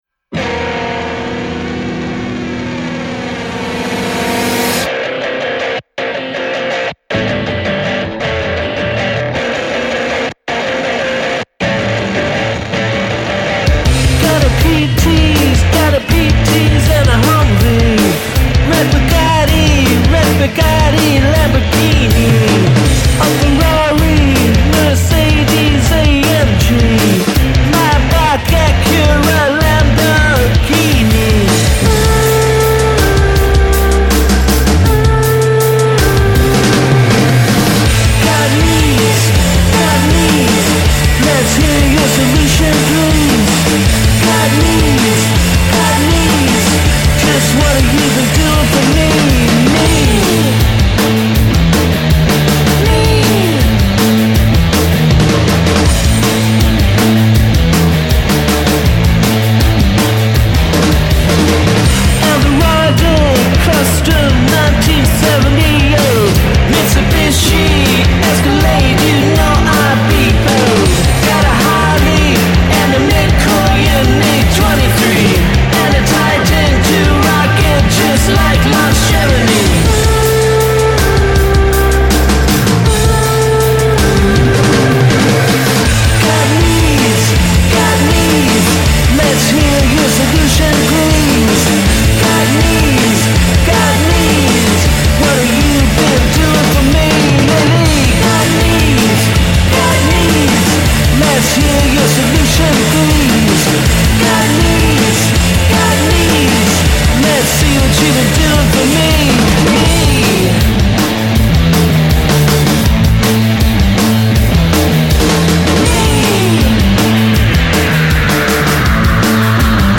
Here’s some more rocky rock